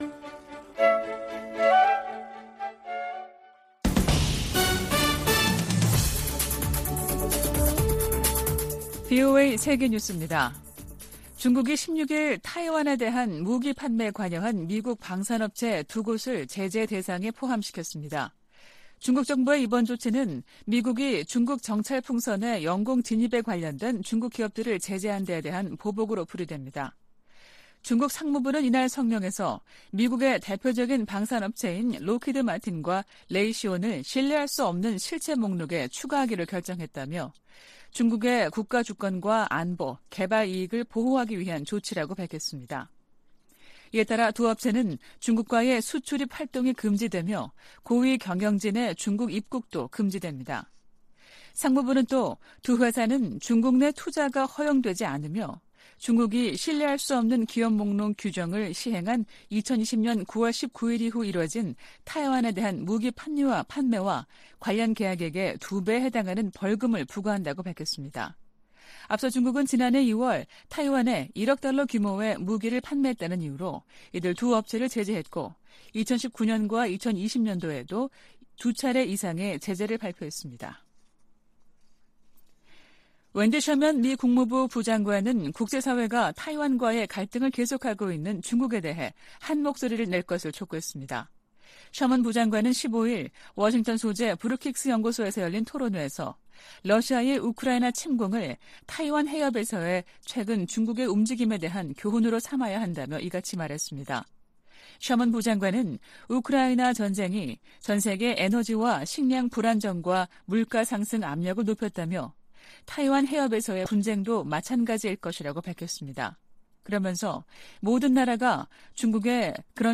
VOA 한국어 아침 뉴스 프로그램 '워싱턴 뉴스 광장' 2023년 2월 17일 방송입니다. 한국 정부의 국방백서가 6년만에 북한 정권과 군을 다시 적으로 명시했습니다. 미 국무부의 웬디 셔먼 부장관이 한국, 일본과의 외교차관 회담에서 북한의 도발적인 행동을 규탄하고 북한의 외교 복귀를 촉구했습니다. 미 하원에서 다시 재미 이산가족 상봉 결의안이 초당적으로 발의됐습니다.